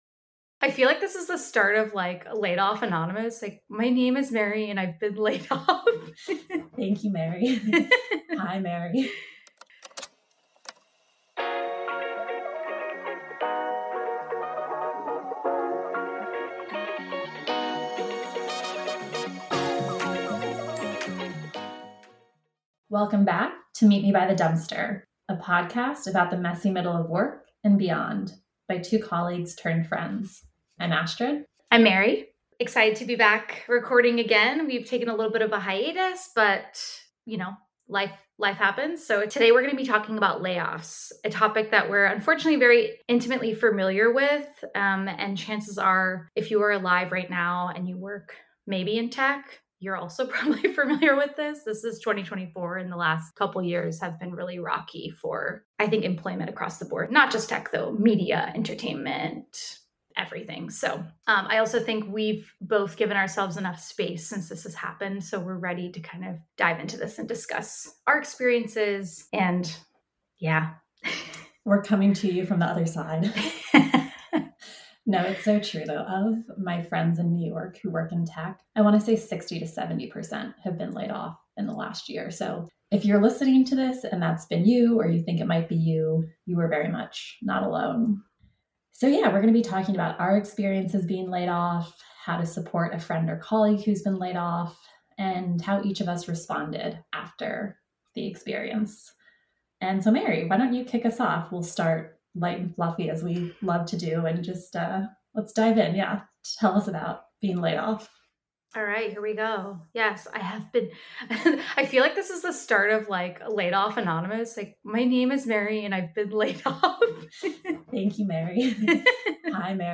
two colleagues turned confidantes discuss the messy middle of all things work. At the dumpster, we dissect the thrill of finding a work friend, eye roll inducing jargon, professional façades, work nemeses, and more.